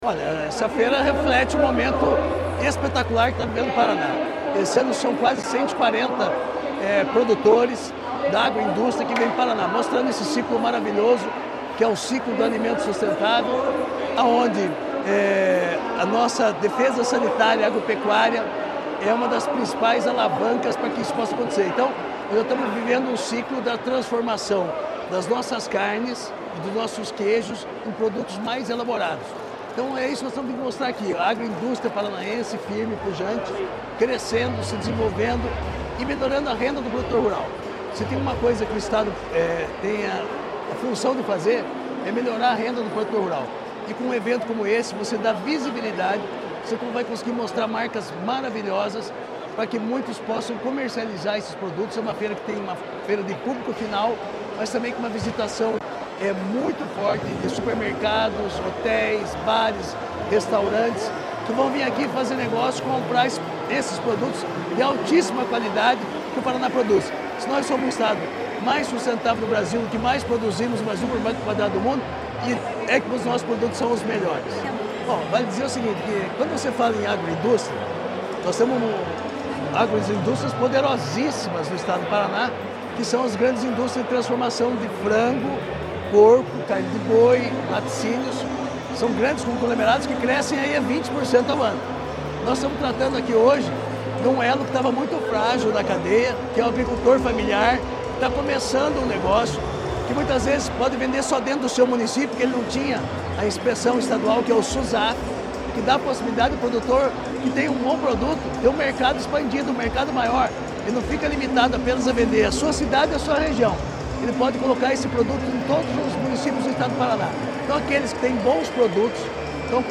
Sonora do secretário da Agricultura e do Abastecimento, Márcio Nunes, sobre a Feira Sabores do Paraná 2025